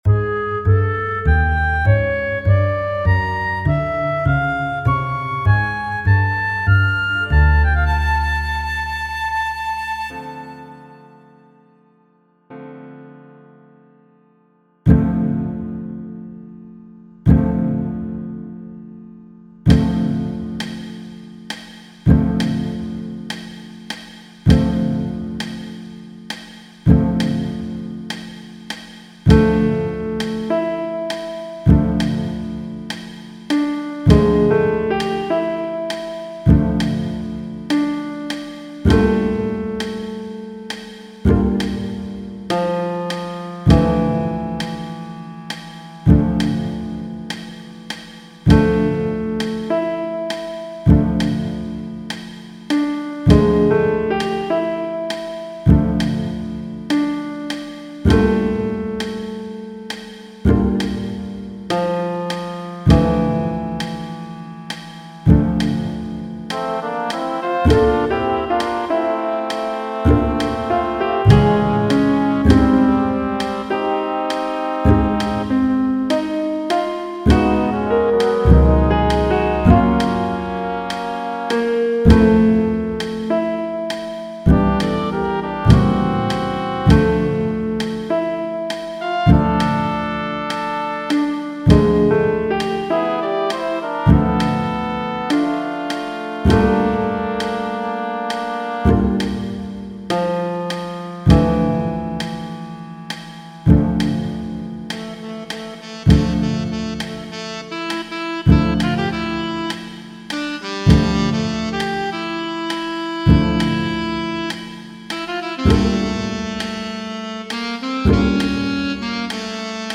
Vocal, Trumpet, Sax, Trombone, Piano, Bass, Drums
All audio files are computer-generated.